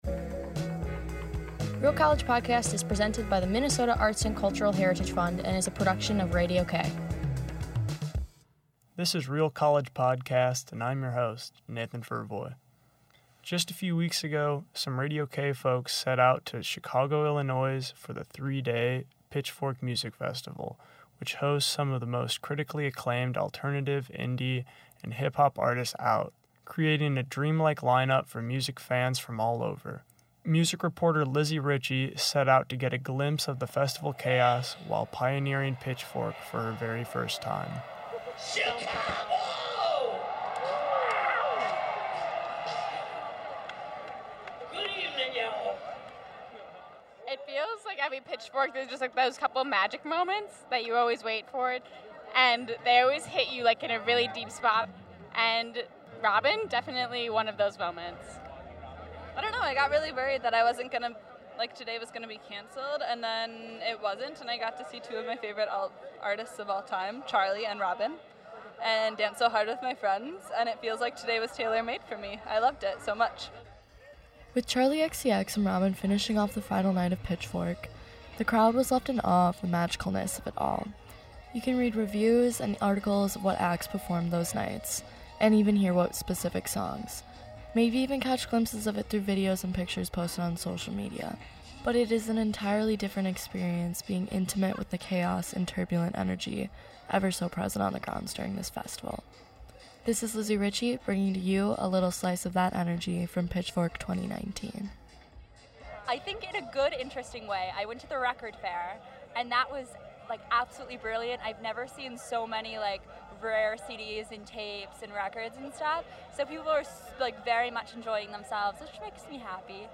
In this episode, RCP brings you to the Pitchfork music festival including a special interview with artist Lala Lala. We also investigate the state of the debate over climate change and ask what exactly goes on at a puppeteer convention.